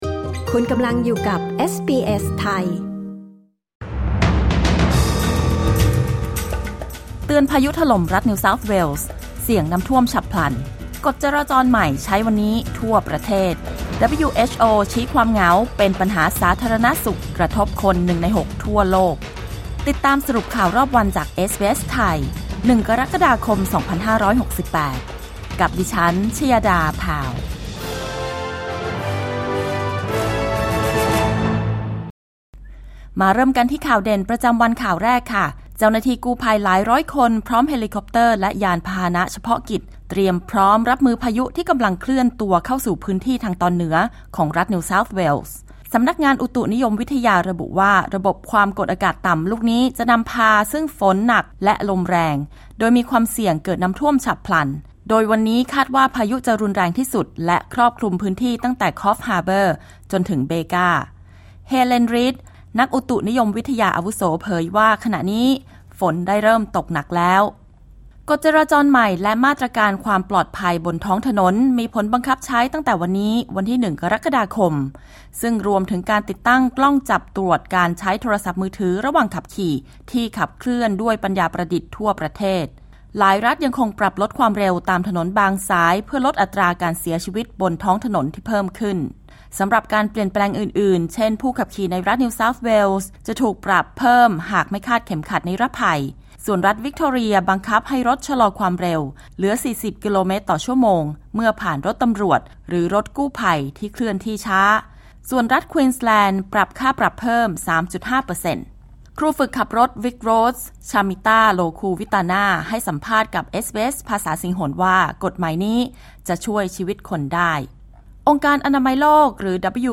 สรุปข่าวรอบวัน 01 กรกฎาคม 2568